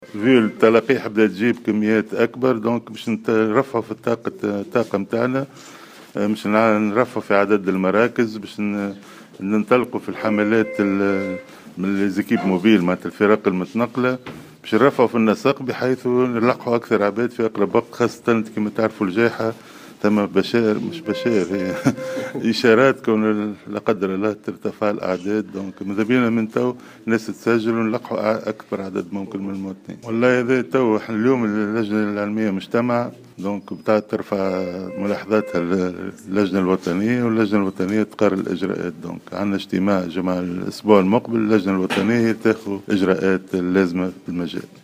أكد وزير الصحة فوزي مهدي، في تصريح للجوهرة أف أم، أن اللجنة العلمية لمكافحة فيروس كورونا، تعقد اليوم السبت، اجتماعا لمتابعة الوضع الوبائي في البلاد، في ظل ارتفاع نسق العدوى بالفيروس، على أن ترفع ملاحظاتها إلى اللجنة الوطنية التي ستتخذ الإجراءات اللازمة في الخصوص خلال اجتماعها المقرر خلال الأسبوع القادم.